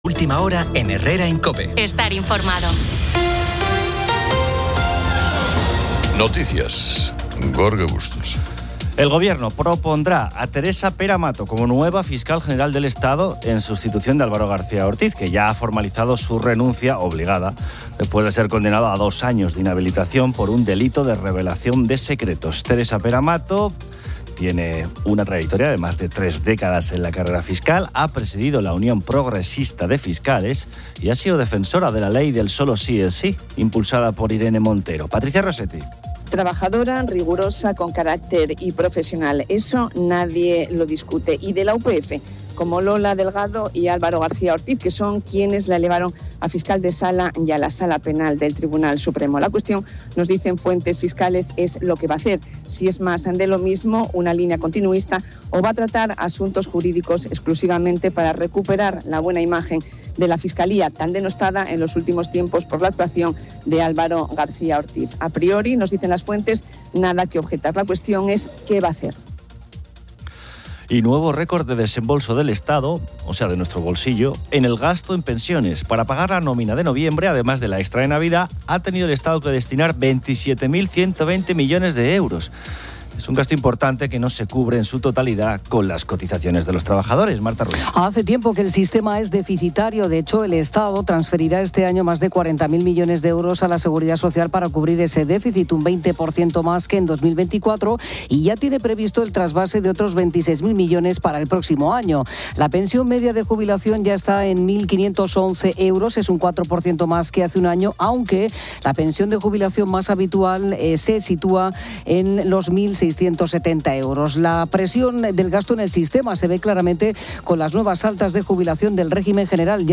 El programa debate sobre el "amigo invisible", con oyentes compartiendo experiencias de regalos originales, controvertidos o el juego de robarlos.